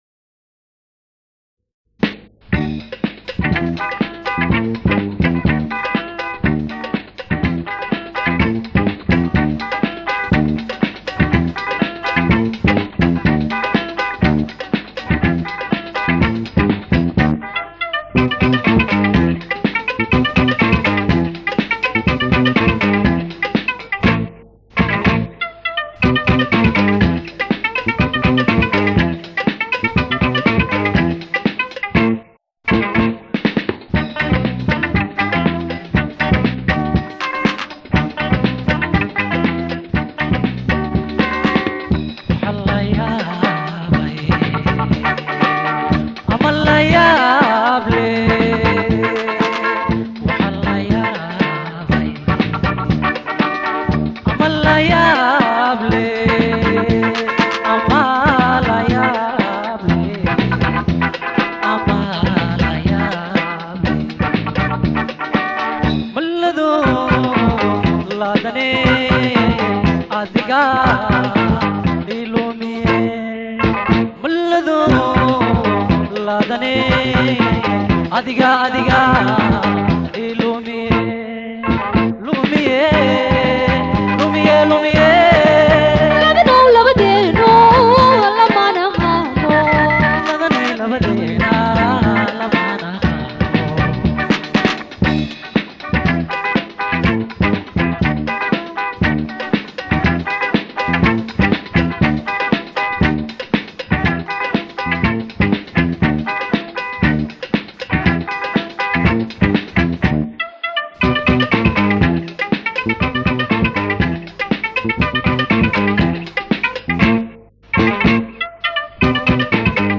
Incroyable morceau somalien !